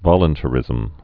(vŏlən-tə-rĭzəm)